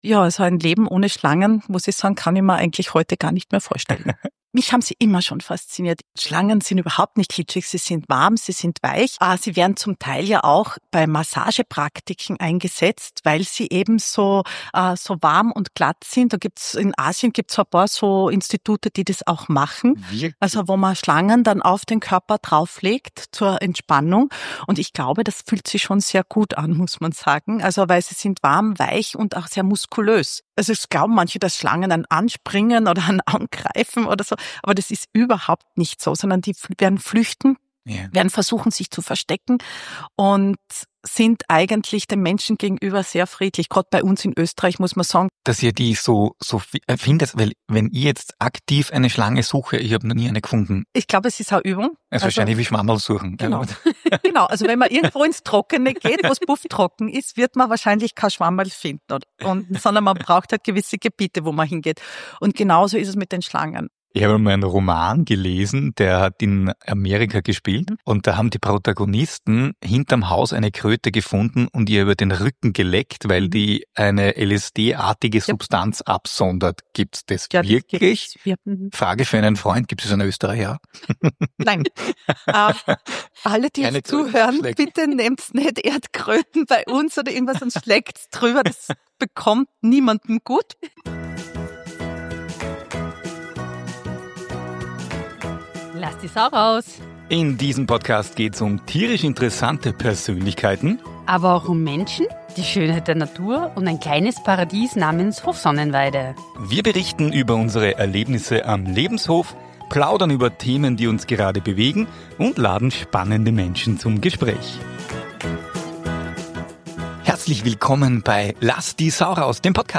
Wer bis jetzt noch nicht von Schlagen und Amphibien begeistert war, wird es nach diesem Interview sein.